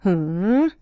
hmm.wav